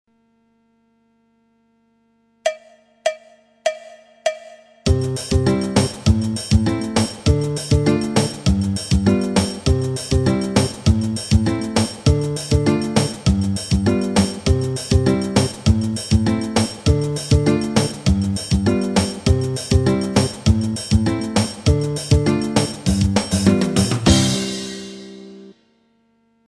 Il se joue quant à lui d'une manière assez rapide avec triangle, zabumba, shaker.
Variante 1 figure guitare baião 2.